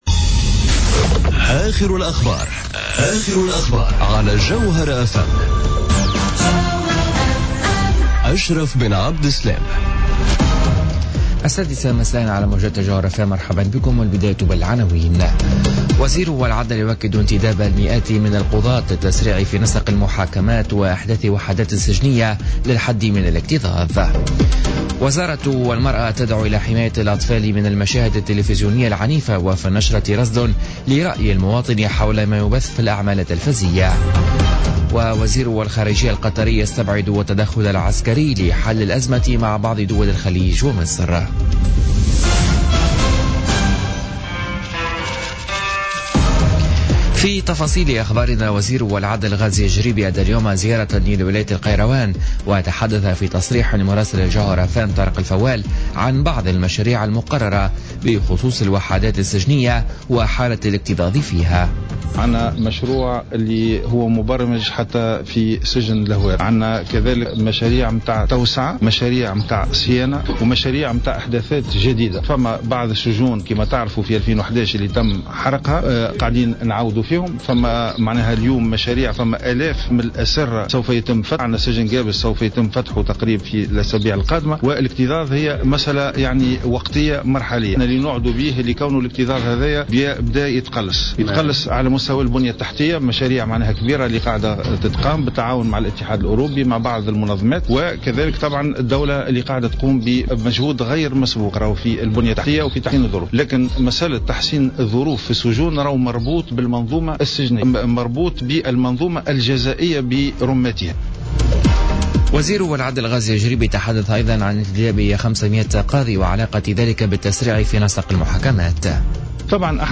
نشرة أخبار السادسة مساء ليوم الخميس 8 جوان 2017